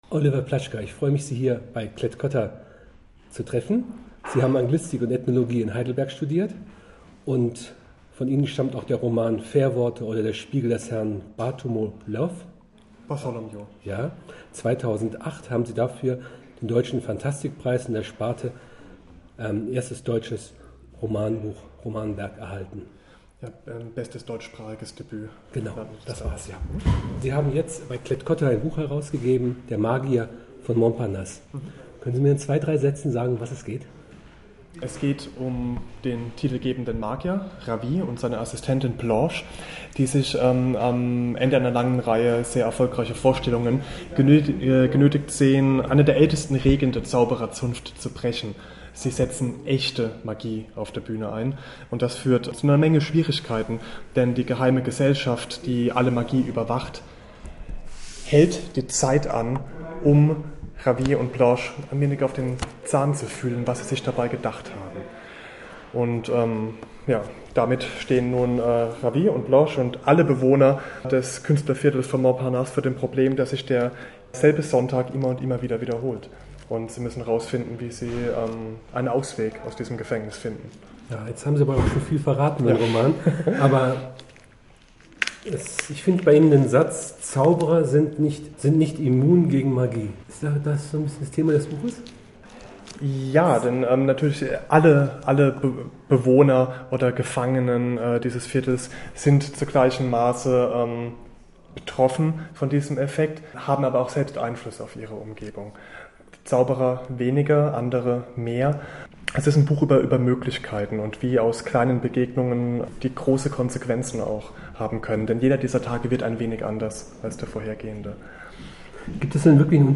Aber hören Sie sich das > Gespräch mit dem Autor der Magier von Montparnasse (mp3) hier nur an, wenn Sie vor der Lektüre möglichst wenig über das Buch wissen wollen.